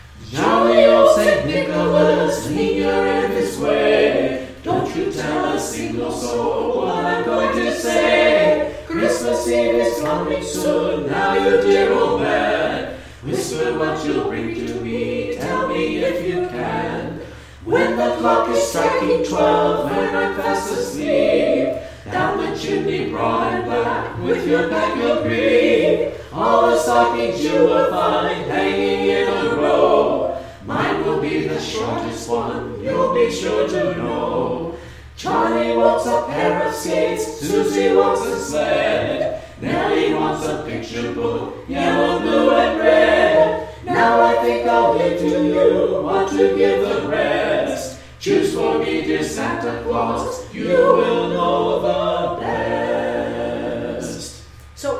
Music 1 Minute
Another Santa song, this one more trusting of Santa to choose the best gift, but also anxious to know beforehand what it is.